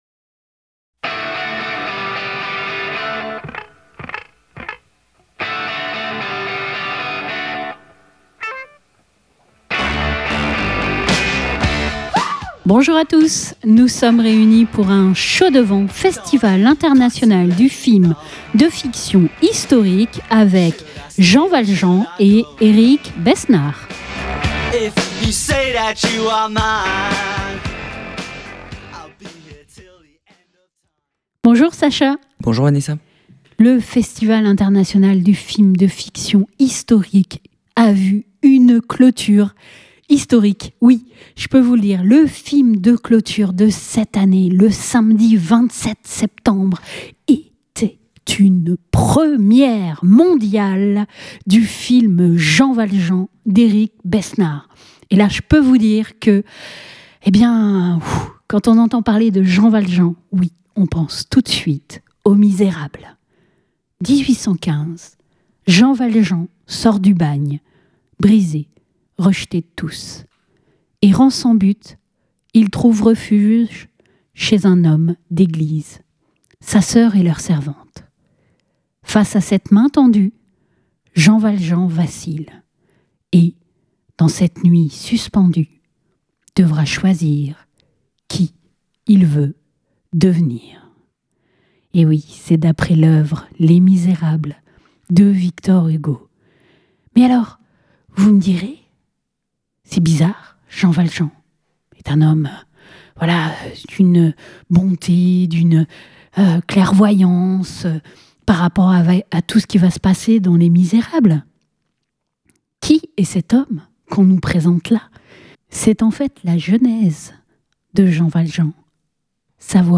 Nous avons eu la chance de nous entretenir avec Eric Besnard juste avant la cérémonie de clôture.